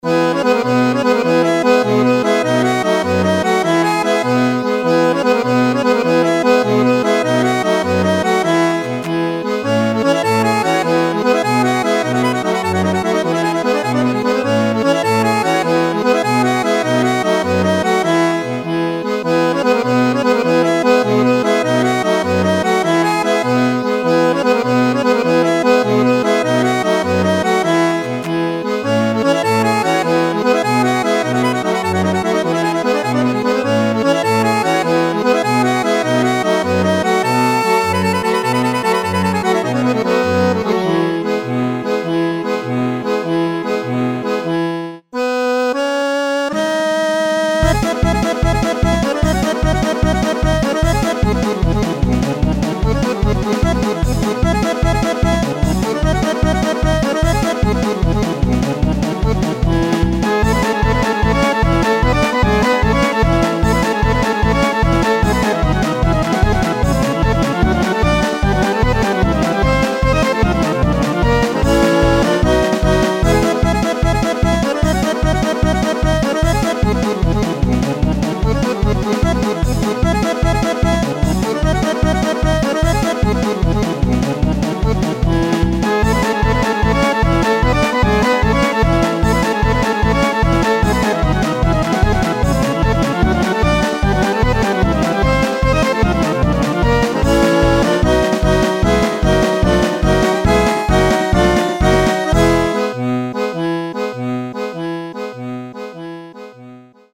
BPM53-150
Audio QualityMusic Cut
POLKA